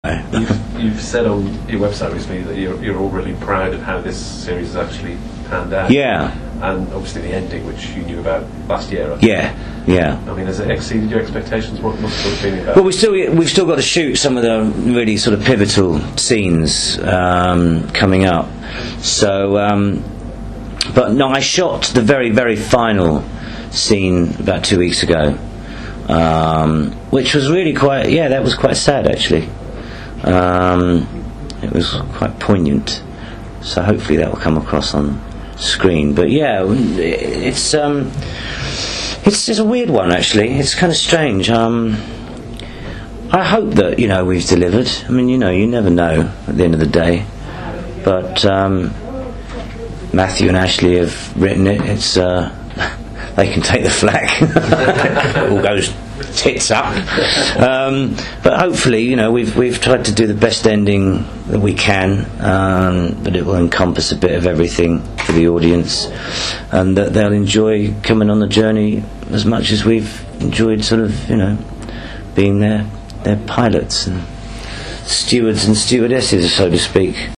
So here are a few I’ve edited from my interview with Phil and saved in MP3 format.
As you may have read in the feature, our final series conversation took place in his dressing room on set in Bermondsey just days before filming ended in February.